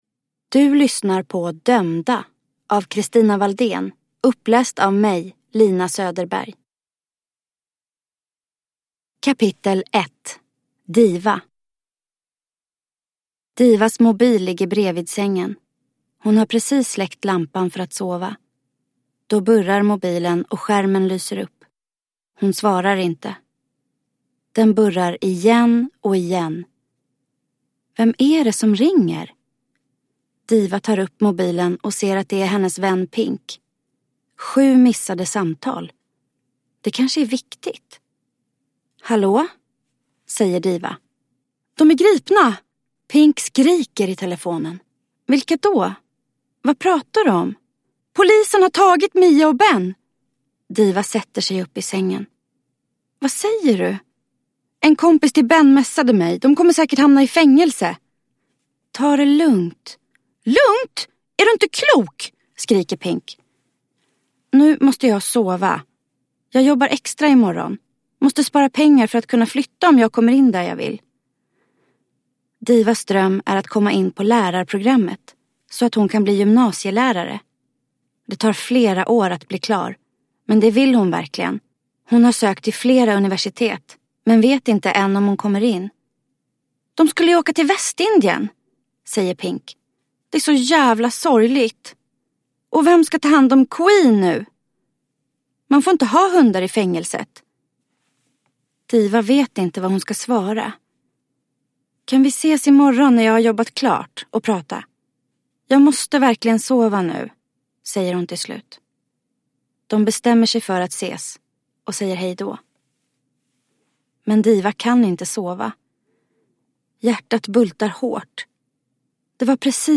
Dömda? – Ljudbok